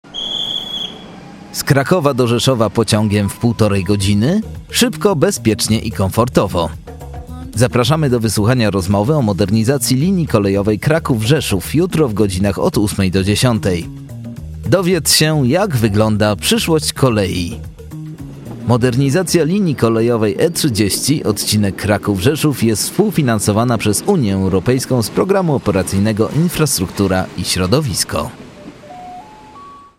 2017-12 audycje radiowe E30 (spot)